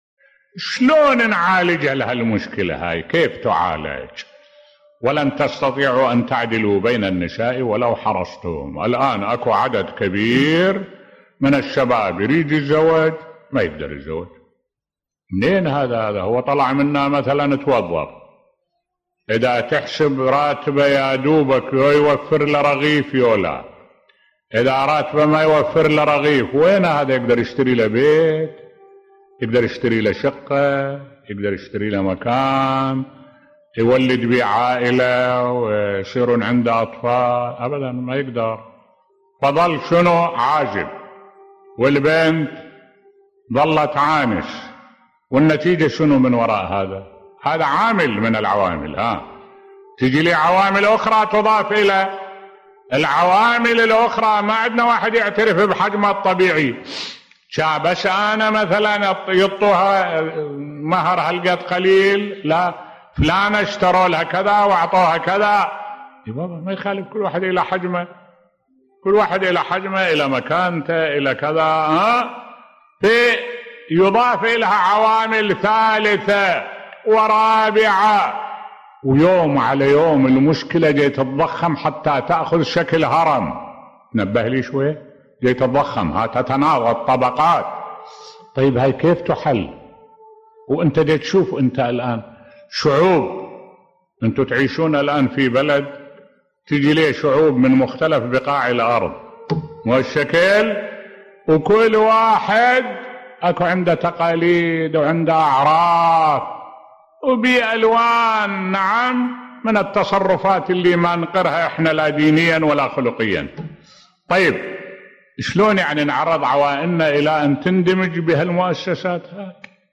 ملف صوتی كيف نعالج مسألة العقبات في الزواج و غلاء المهور بصوت الشيخ الدكتور أحمد الوائلي